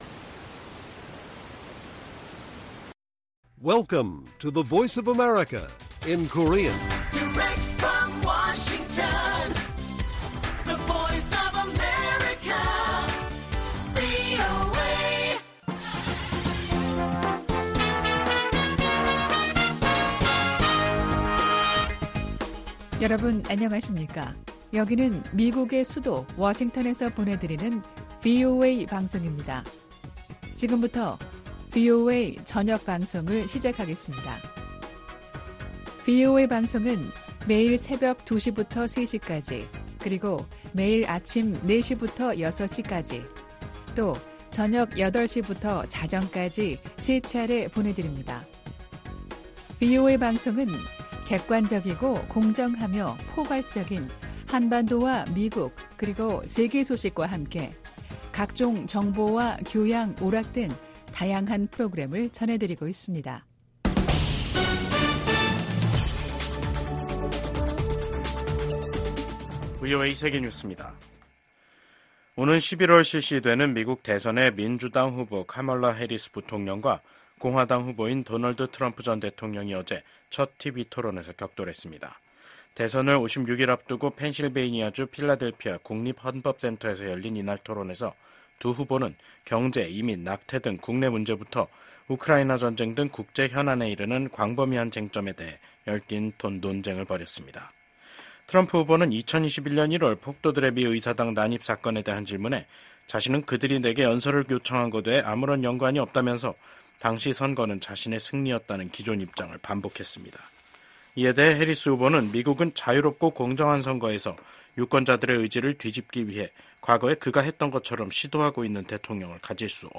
VOA 한국어 간판 뉴스 프로그램 '뉴스 투데이', 2024년 9월 11일 1부 방송입니다. 오는 11월 대선에서 맞붙는 카멀라 해리스 부통령과 도널드 트럼프 전 대통령이 TV 토론회에 참석해 치열한 공방을 벌였습니다. 미국, 한국, 일본 간 협력 강화를 독려하는 결의안이 미 하원 본회의를 통과했습니다. 서울에선 68개 국가와 국제기구 고위 인사들이 참석한 가운데 다자 안보회의체인 서울안보대화가 열렸습니다.